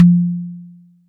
Royality free tom drum single hit tuned to the F note. Loudest frequency: 980Hz
• Big Tome Drum Sample F Key 20.wav
big-tome-drum-sample-f-key-20-kkx.wav